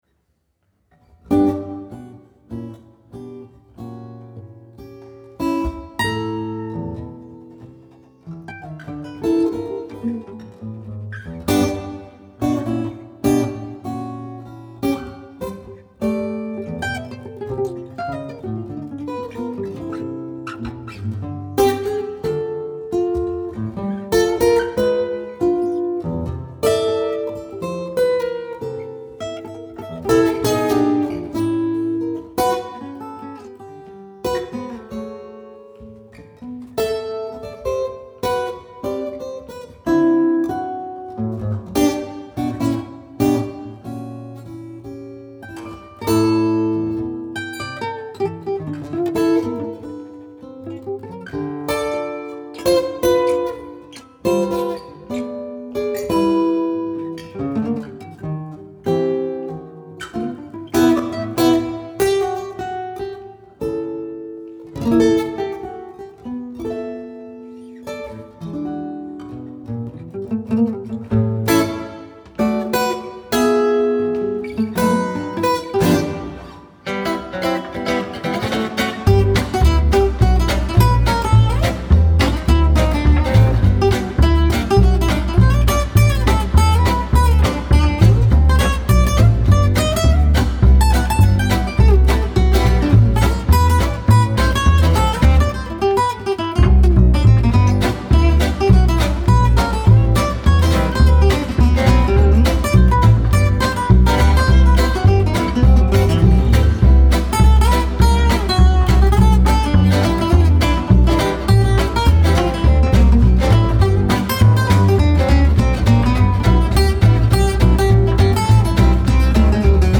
2 x Guitars, Double Bass